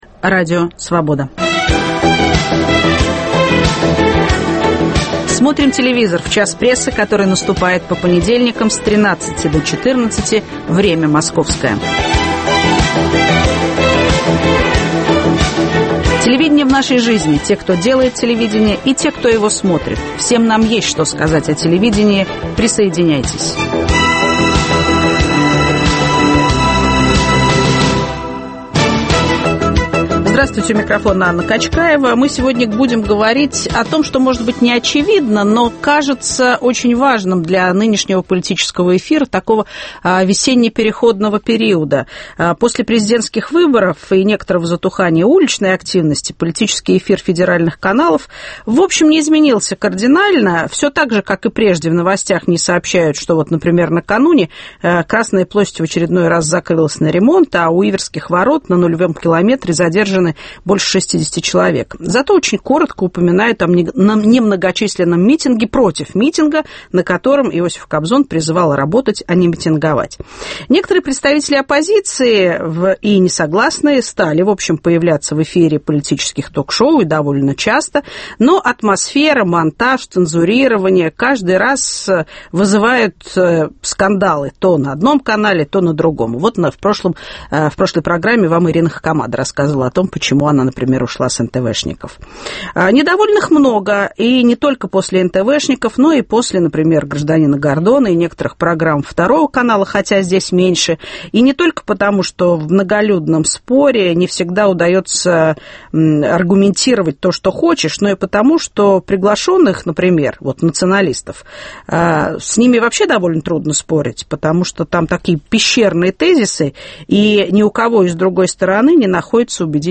Кто кого: идеологическая война в политических телепрограммах. В студии - ведущий передачи "Исторический процесс" Николай Сванидзе и журналист и гражданский активист Сергей Пархоменко.